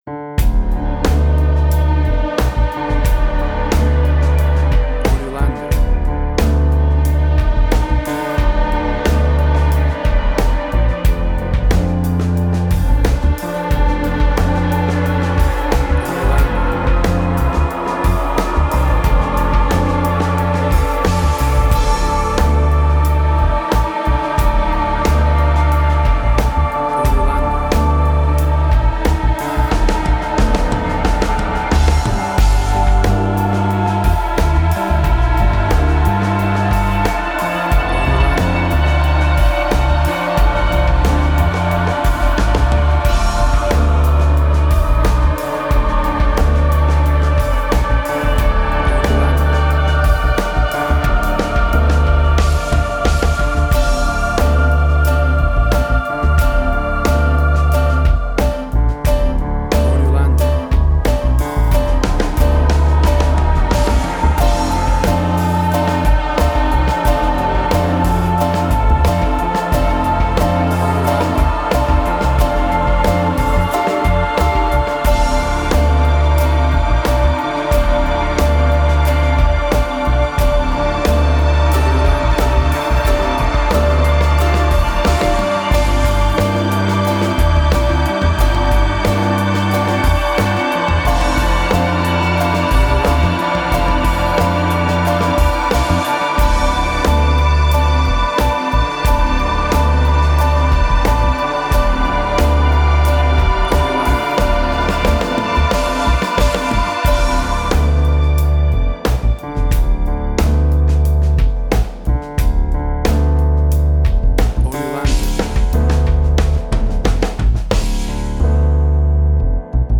Indie Quirky.
Tempo (BPM): 90